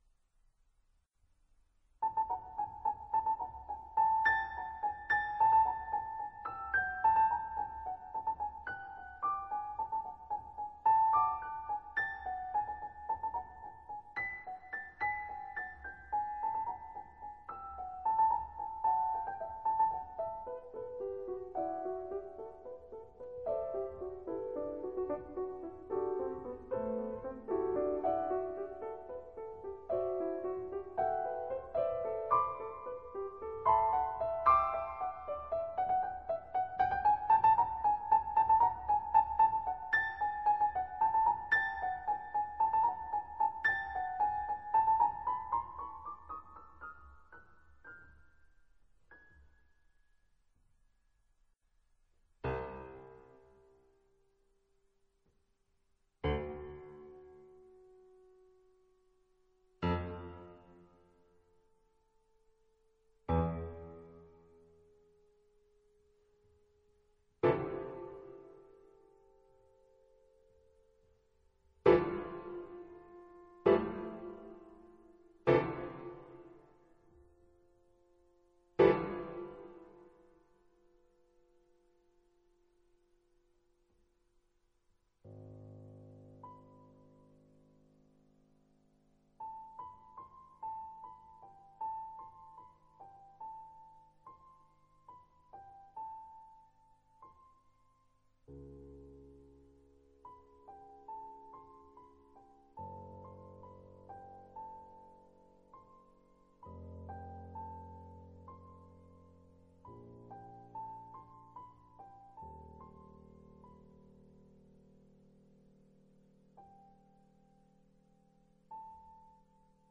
Pièces pour piano solo *